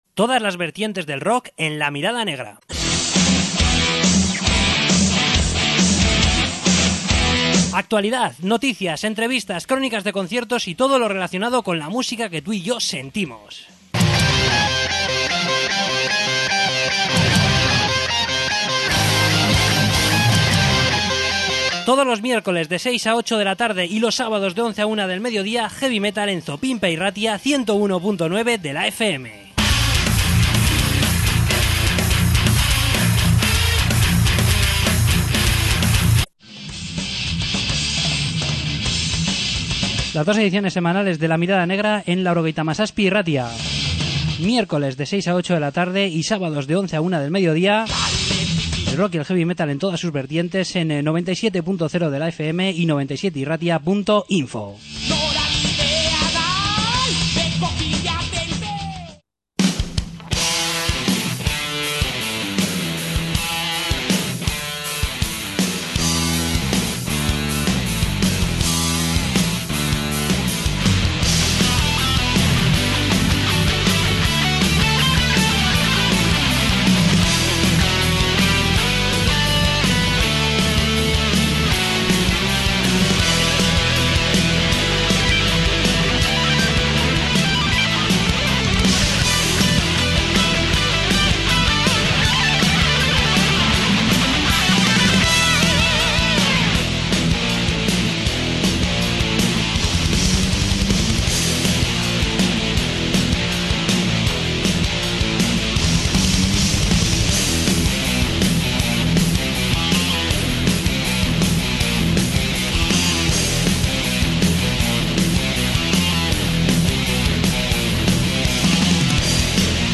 Entrevista con The Broken Horizon